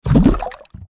tt_s_ara_cmg_waterCoolerFill.mp3